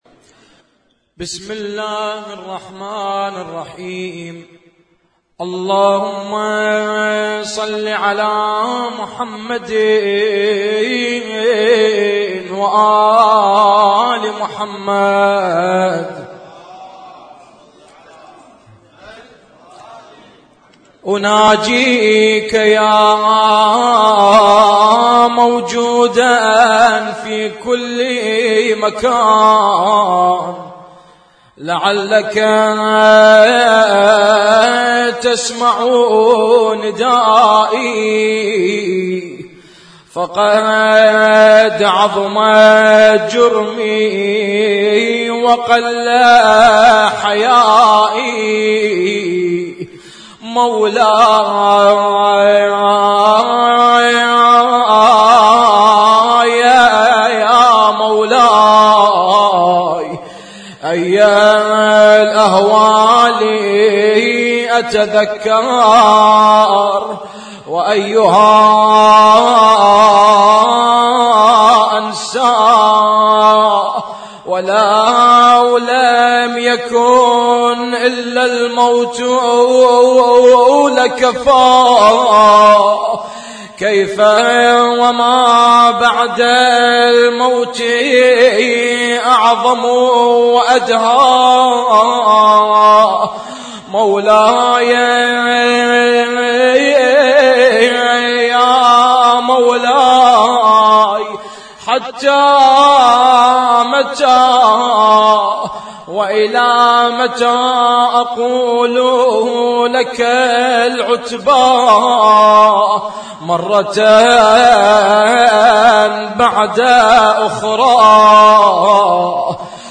احياء ليلة 23 رمضان 1436
اسم التصنيف: المـكتبة الصــوتيه >> الادعية >> ادعية ليالي القدر